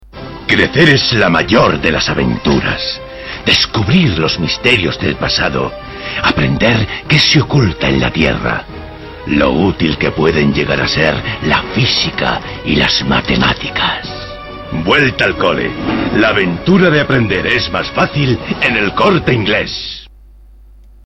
Es un anuncio.
Las linternas de los niños, la música y el título recuerdan la película de Indiana Jones.